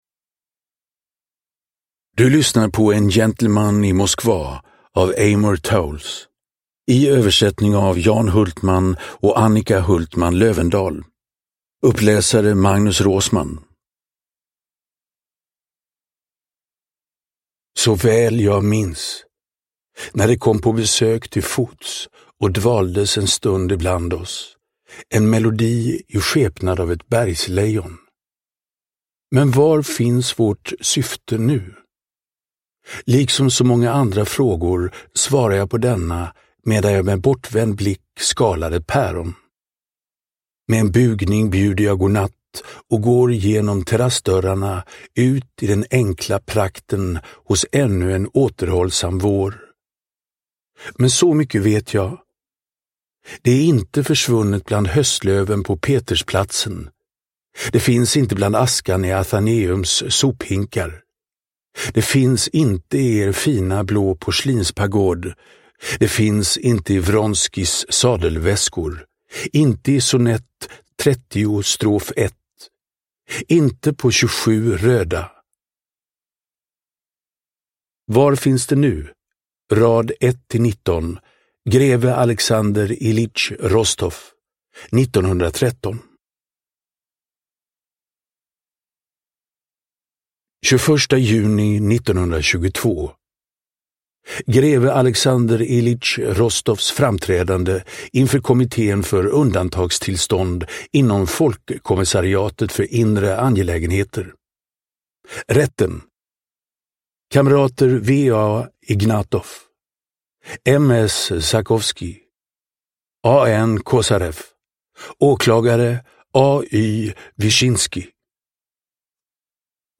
En gentleman i Moskva – Ljudbok – Laddas ner